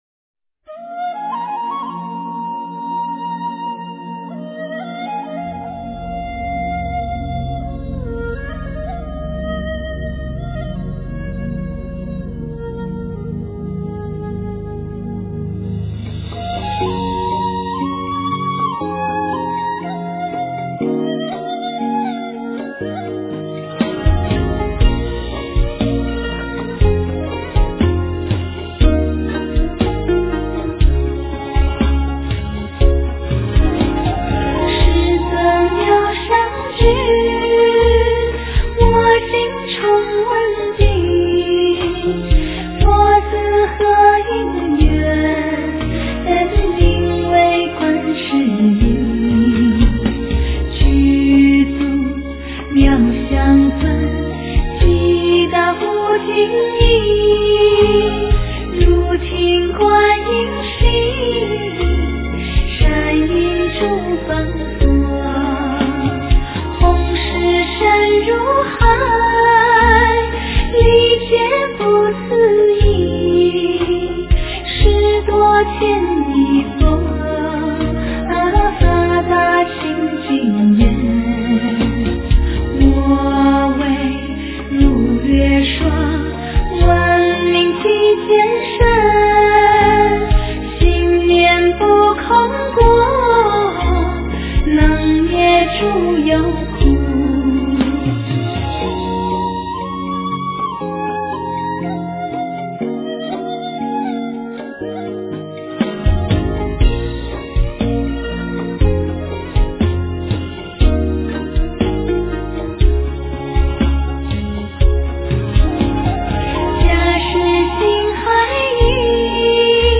诵经
佛音 诵经 佛教音乐 返回列表 上一篇： 地藏经-校量布施功德缘品第十 下一篇： 大吉祥经-南传 相关文章 般若波罗密多心经 般若波罗密多心经--未知...